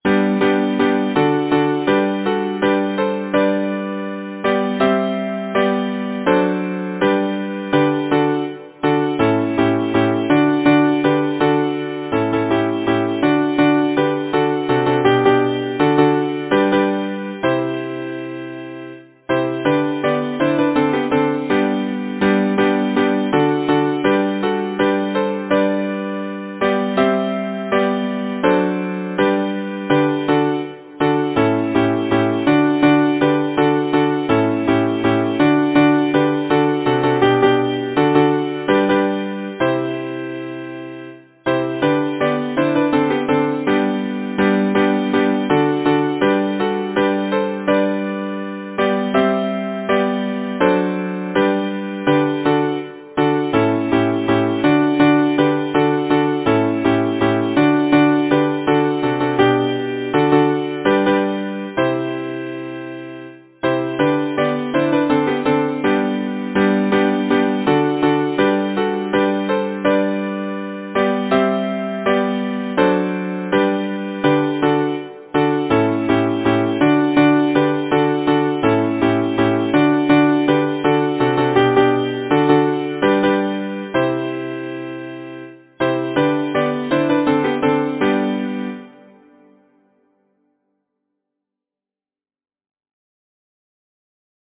Title: “Pretty is as pretty does” Composer: Milton Z. Tinker Lyricist: Alice Cary Number of voices: 4vv Voicing: SATB Genre: Secular, Partsong
Language: English Instruments: A cappella